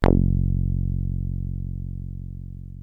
303 F#1 2.wav